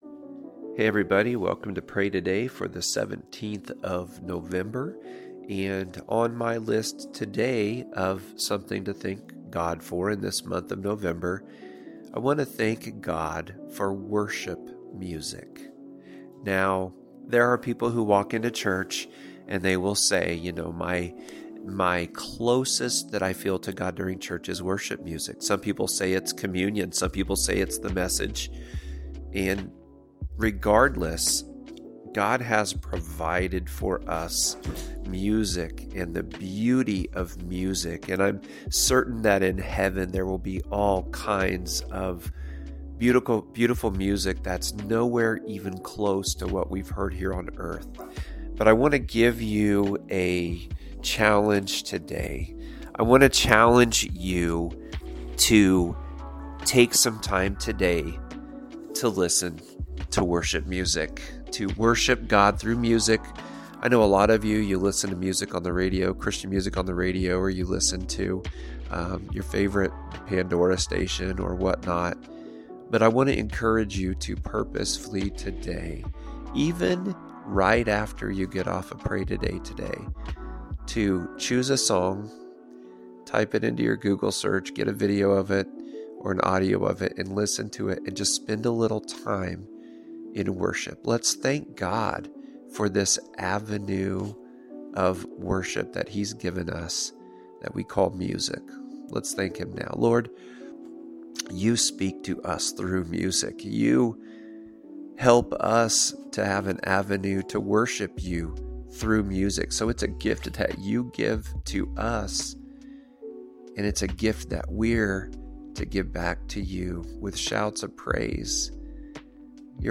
Pray Today | November 17 (Worship Music)